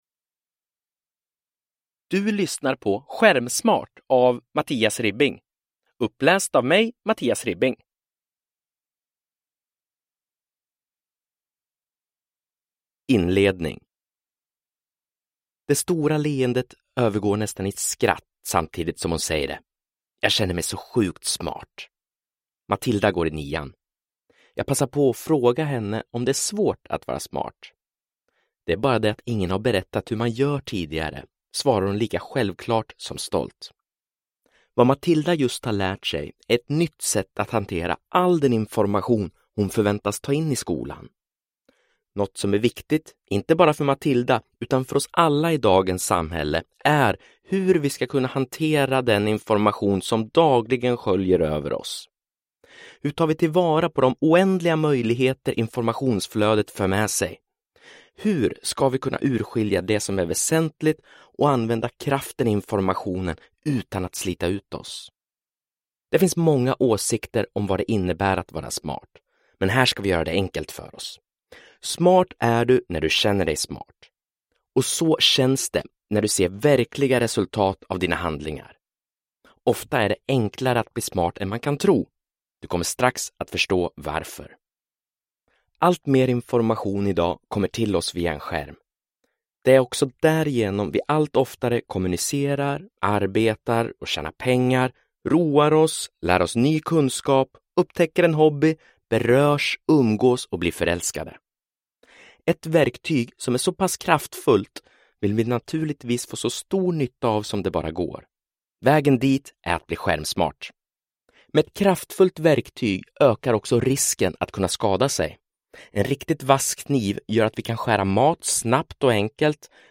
Skärmsmart – Ljudbok – Laddas ner